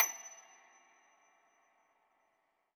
53k-pno28-F6.aif